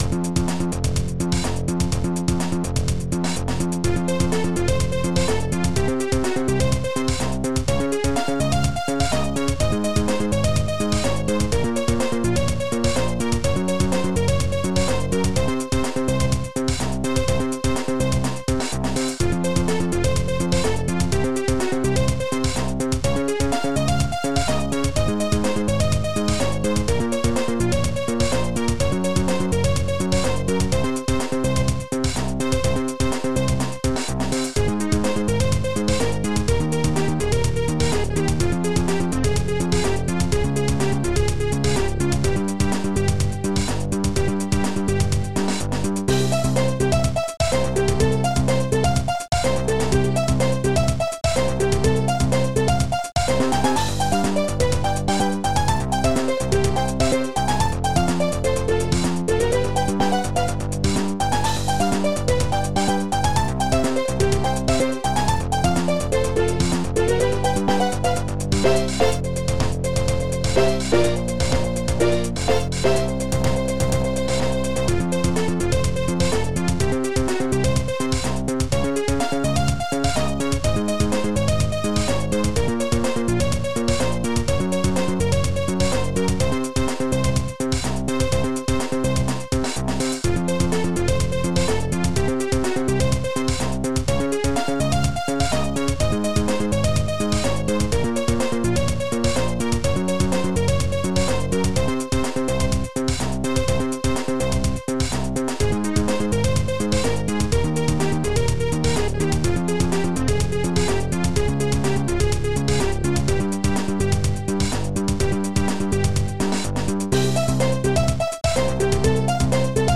Protracker and family
Smak-Bass
processedkick
rim-snare
st-03:Steel+strgs
st-03:VFX-cymbal